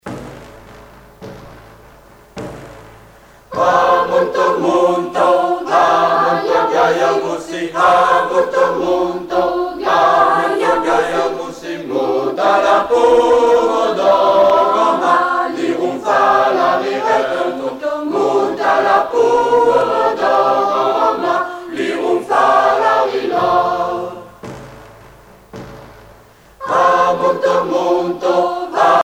Pièce musicale éditée
Catégorie Pièce musicale éditée